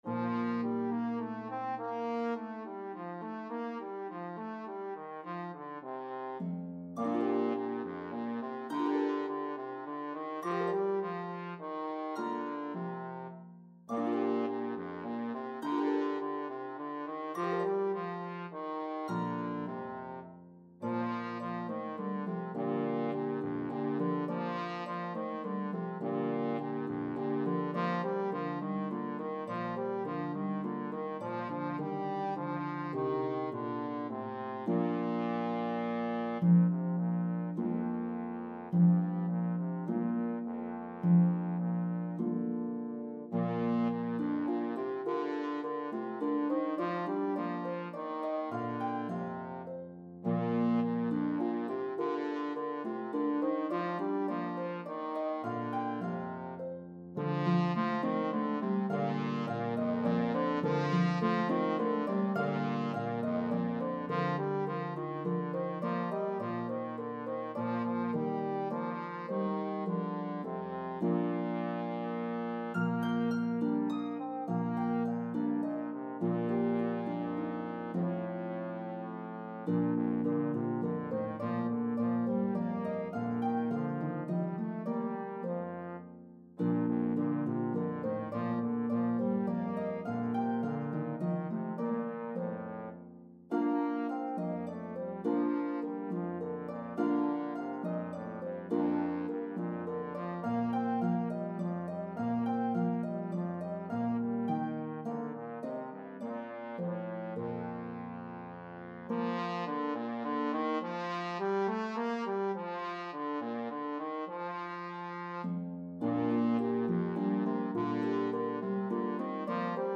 A jubilant arrangement